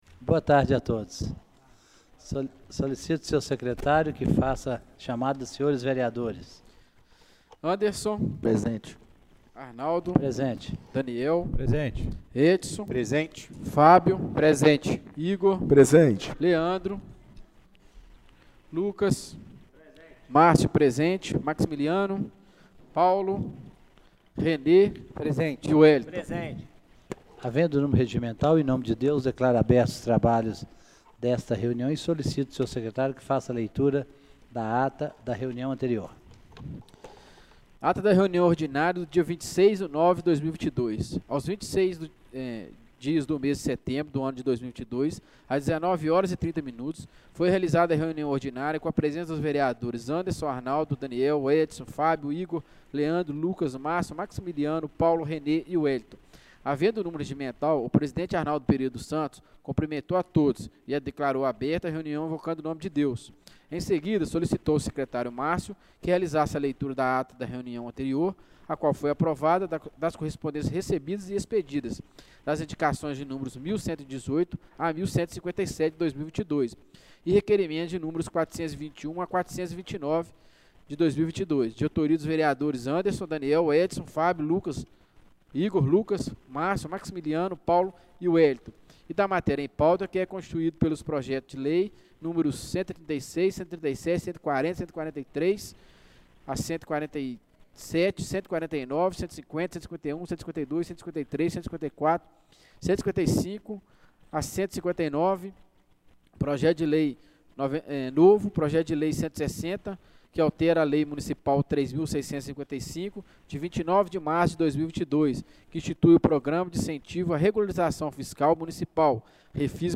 Reunião Extraordinária do dia 28/09/2022